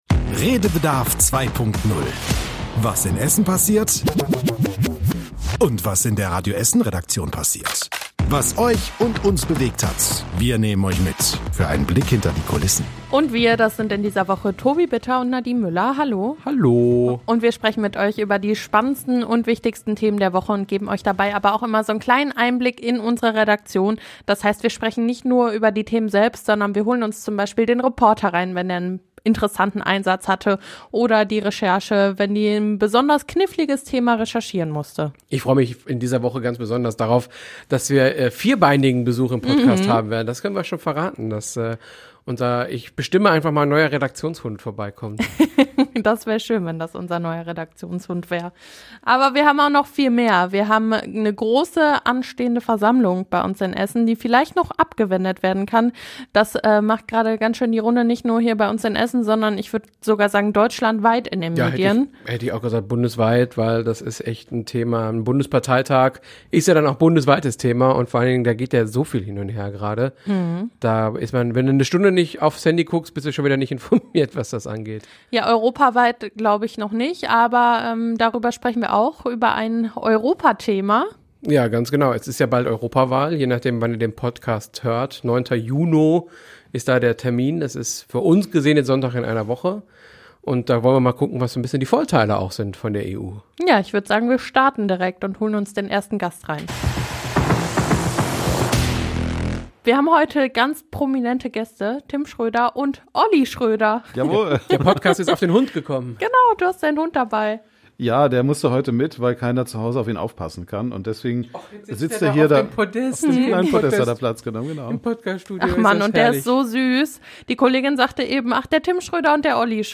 Was da auf uns zukommt, erklären Gäste aus der Nachrichtenredaktion.